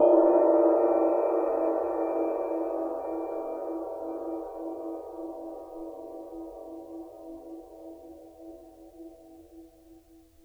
susCymb1-hit_pp_rr1.wav